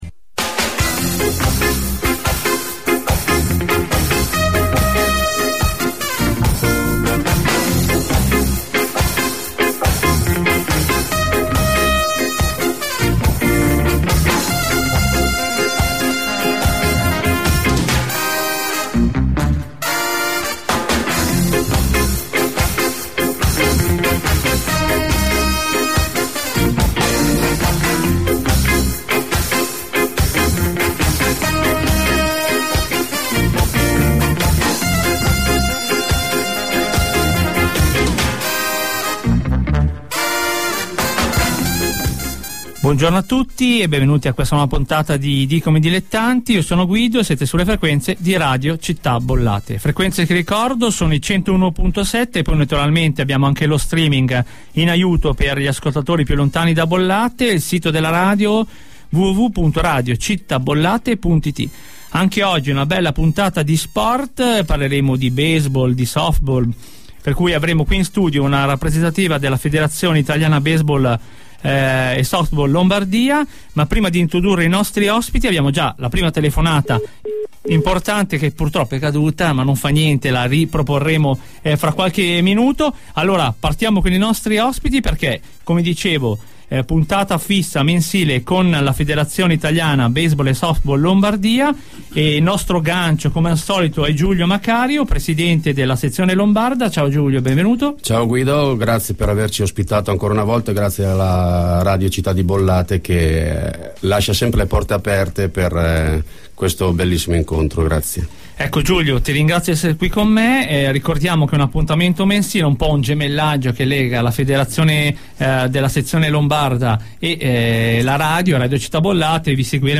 Una delegazione dei Lampi Milano alla trasmissione di Radio Città Bollate.